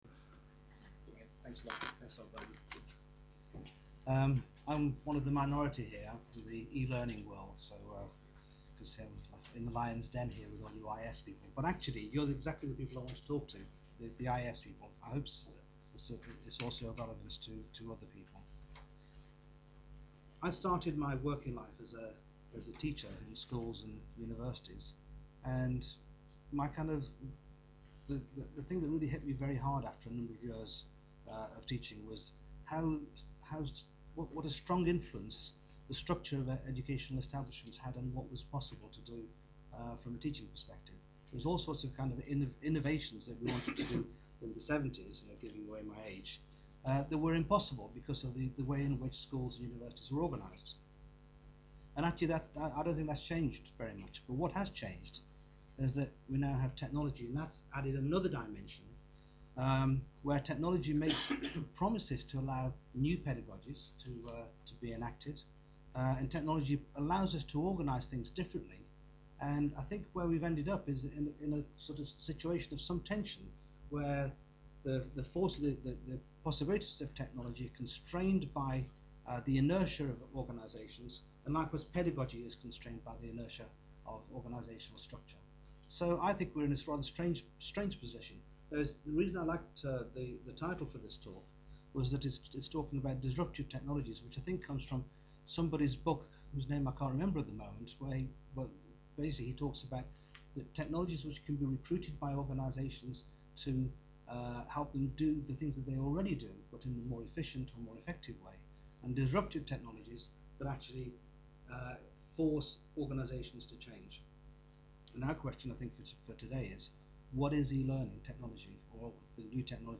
UKOLN - Web Focus - UCISA / UKOLN / CETIS Workshop 2006
Recording of Talk